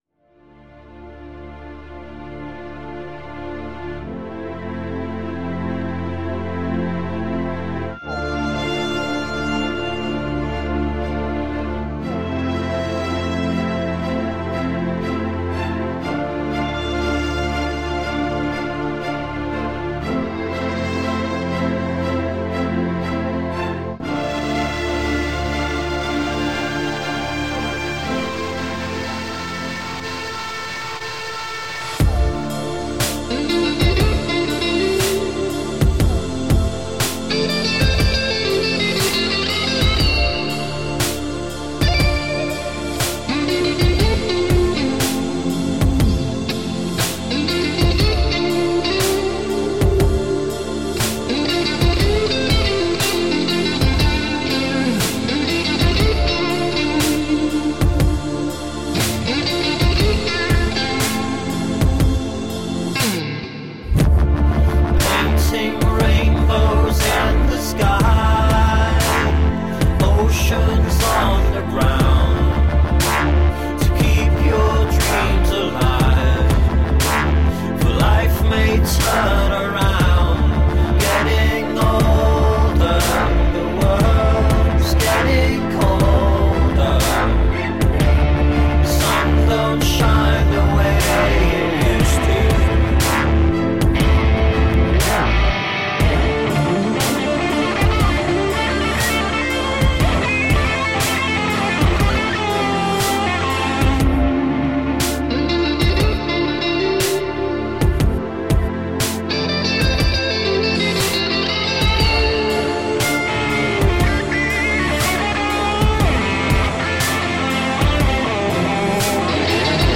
Melodic dubstep and heavy electronic stimulation.
Each track has been carefully crafted via emotion and mood.
Tagged as: Electronica, Industrial